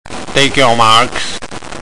Při olympijských hrách, mistrovství světa a jiných soutěžích FINA musí být dán povel "na místa" v angličtině ("Take your marks")
takeyourmarks.mp3